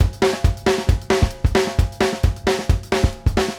CLSHAT GRO-L.wav